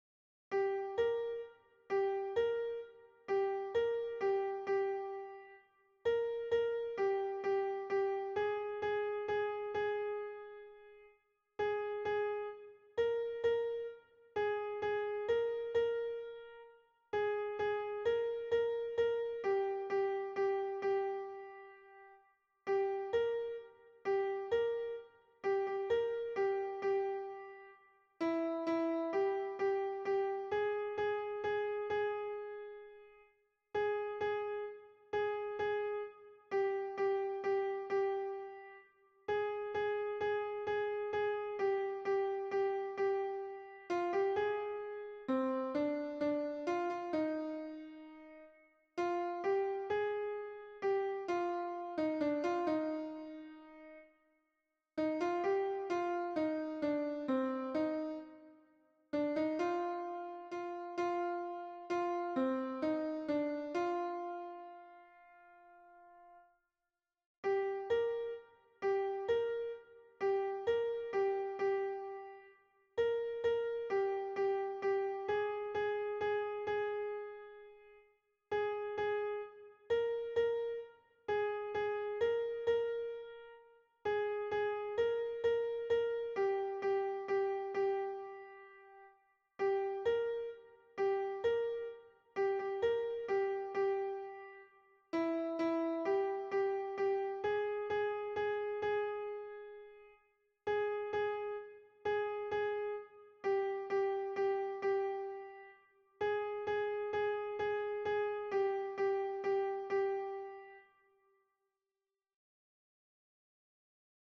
Mp3 version piano
Tenor